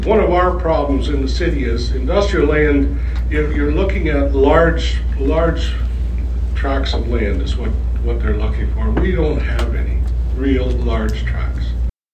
Quinte West Mayor Jim Harrison gave his annual State of the City Address Friday at the Ramada Inn in Trenton, an event hosted by the Quinte West Chamber of Commerce.